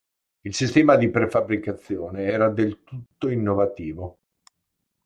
in‧no‧va‧tì‧vo
/in.no.vaˈti.vo/